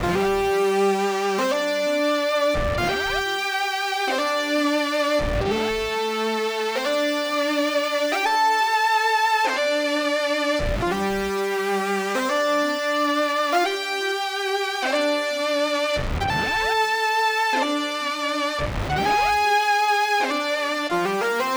03 big lead C.wav